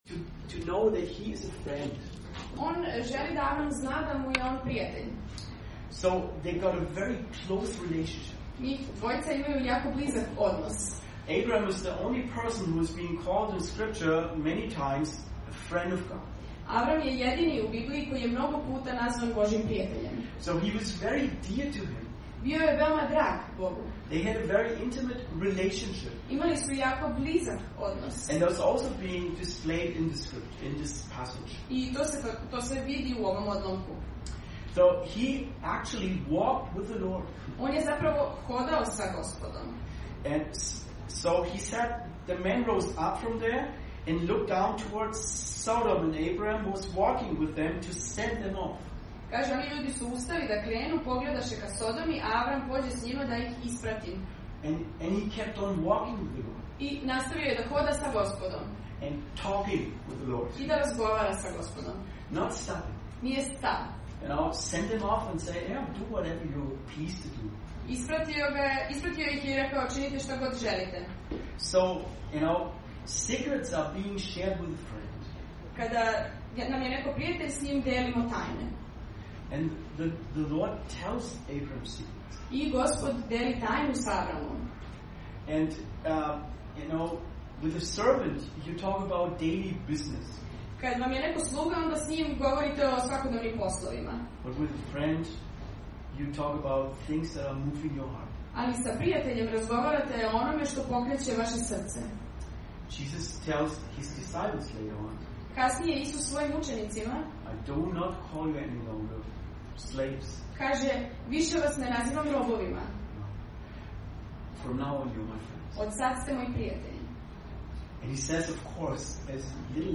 Propoved: Božiji prijatelj ostavlja veliku zaostavštinu - 1. Mojsijeva 18:16-33
Serija: Avram: otac svih koji veruju | Poslušajte propoved sa našeg bogosluženja.